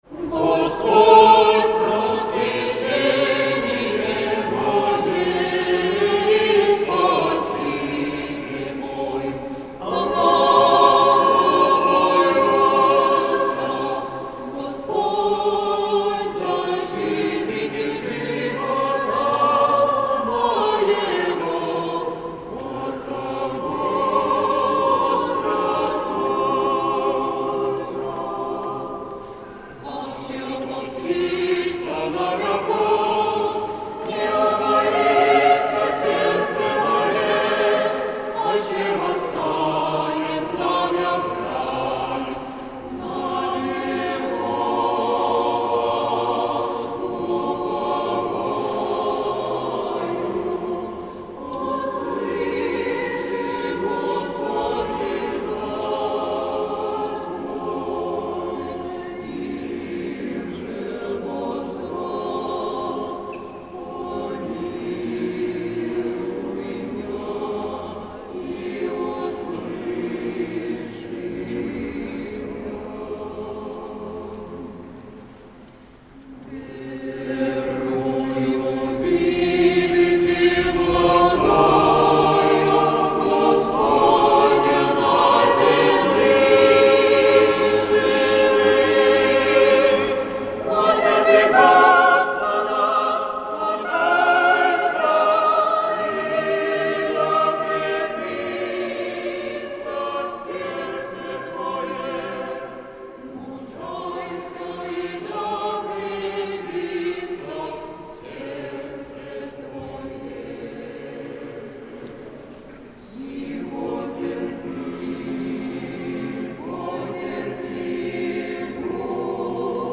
Митрополит Игнатий.
Слово после Литургии в Софийском соборе 29 мая